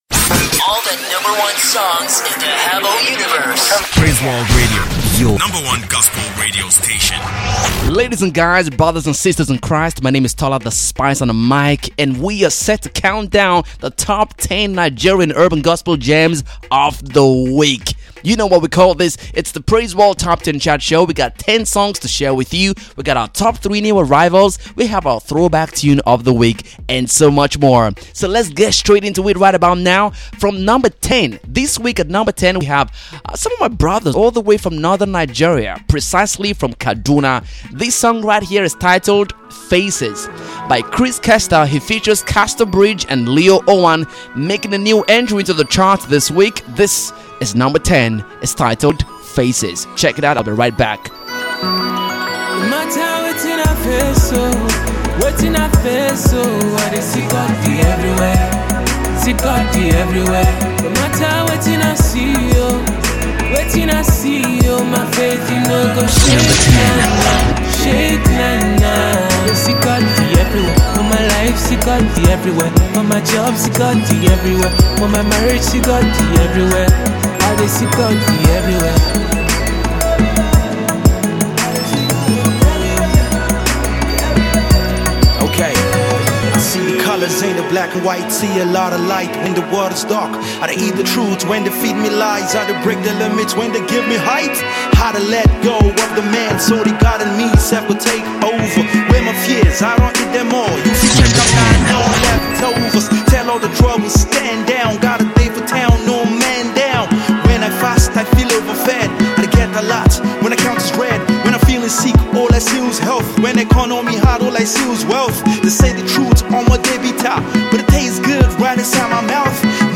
Here is a countdown show of our top 10 Nigerian urban gospel songs of the week. 30 minutes of fun